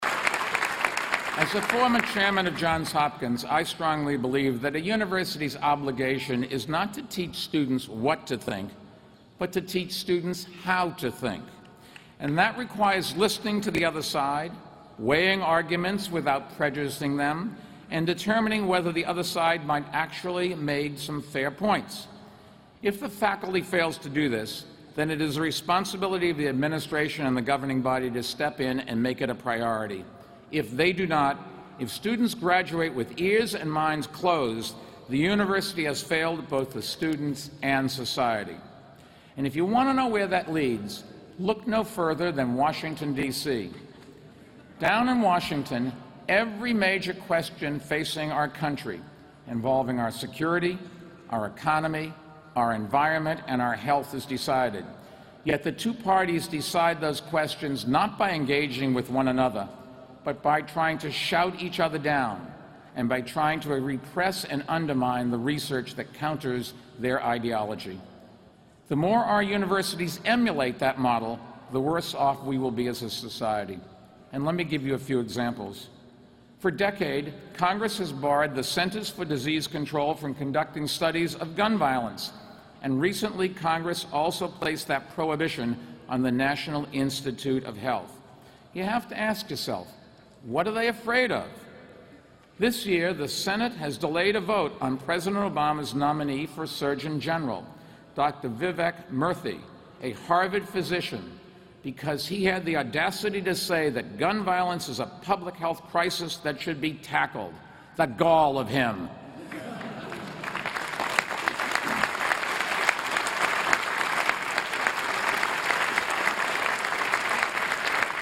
公众人物毕业演讲第17期:迈克尔·彭博哈佛大学2014毕业典礼演讲(9) 听力文件下载—在线英语听力室